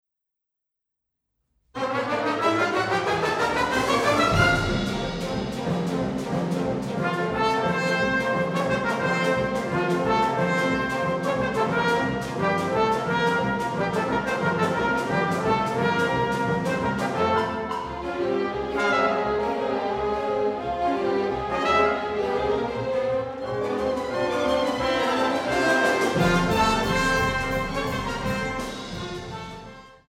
Orchesterwerke verfemter Komponisten